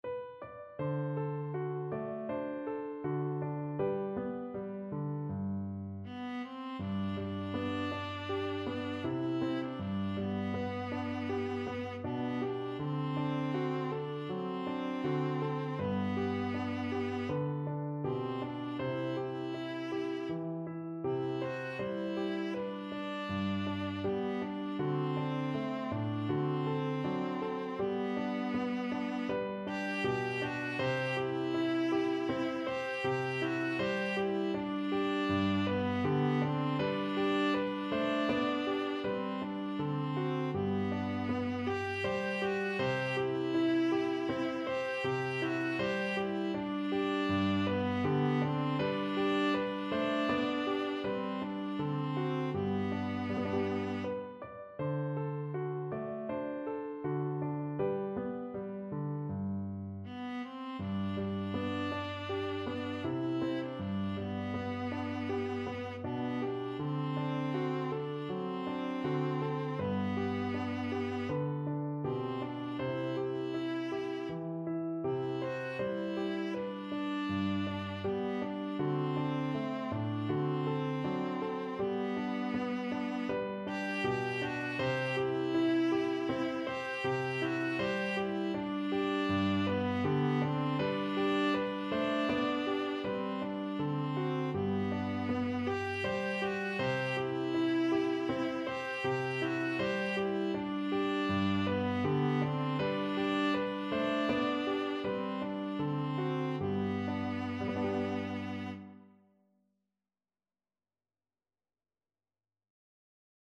Viola version
4/4 (View more 4/4 Music)
Andante
Classical (View more Classical Viola Music)